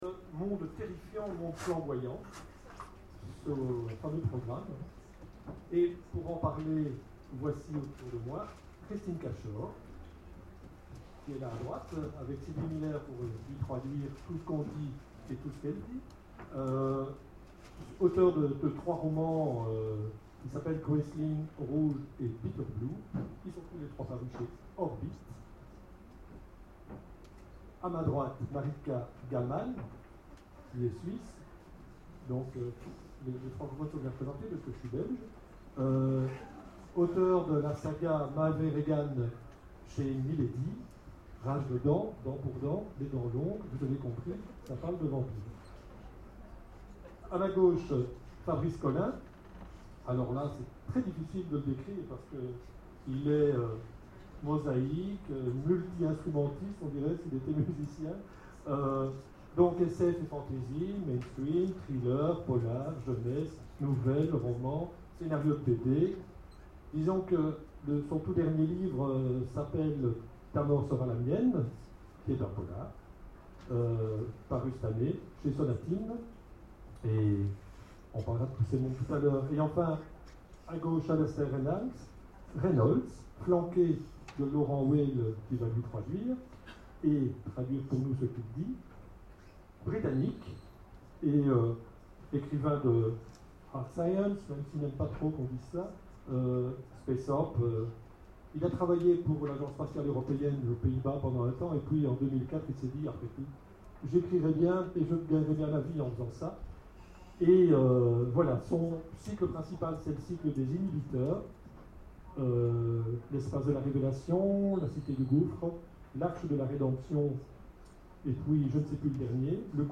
Imaginales 2013 : Conférences Mondes terrifiants